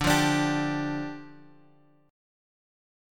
Dmbb5 chord {x 5 3 x 3 3} chord
D-Minor Double Flat 5th-D-x,5,3,x,3,3.m4a